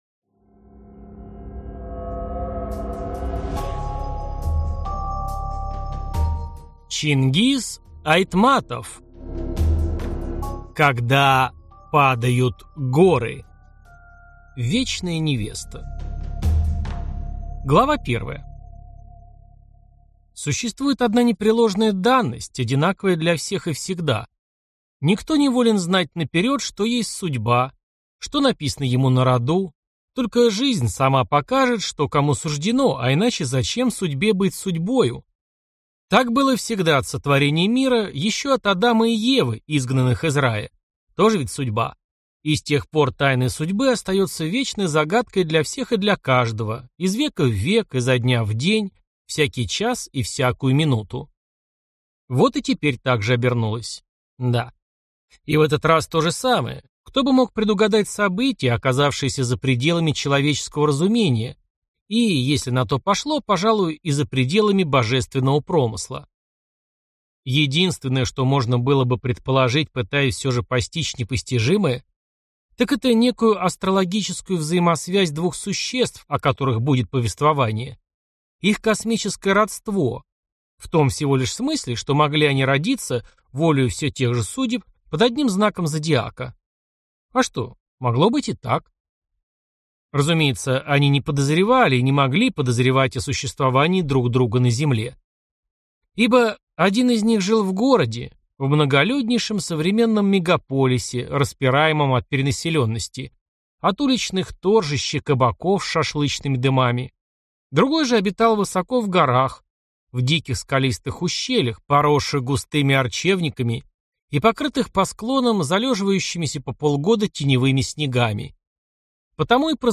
Аудиокнига Когда падают горы | Библиотека аудиокниг